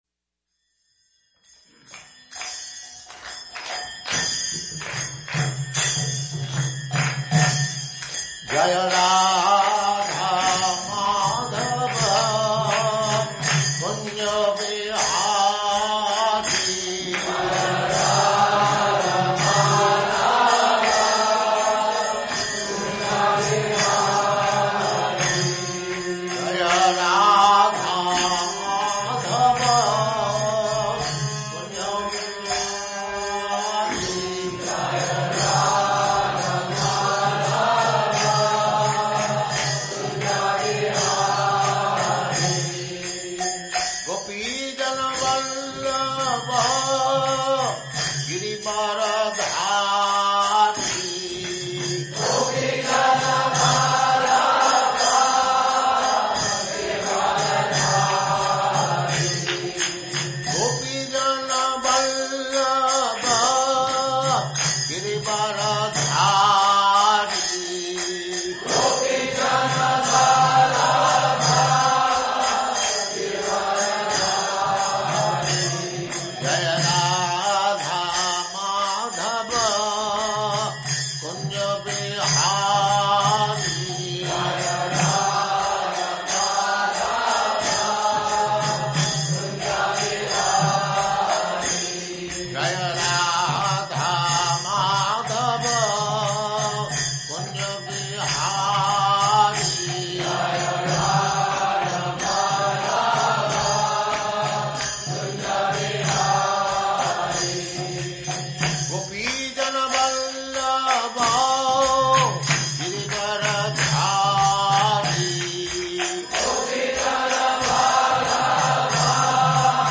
Бхаджан «Джая Радха-Мадхава» исполняется перед лекций Шримад Бхагаватам.
Вариант исполнения v1 — Исполнитель: Шрила Прабхупада
Он написан в свободном лирическом стиле, ориентированном на мелодичность.